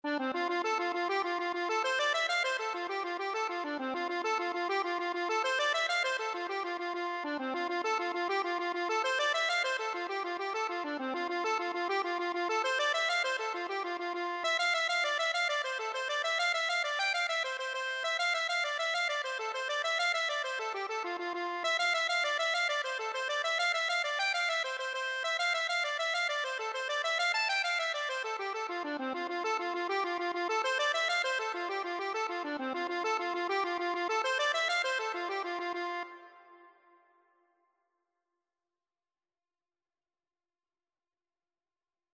6/8 (View more 6/8 Music)
Accordion  (View more Easy Accordion Music)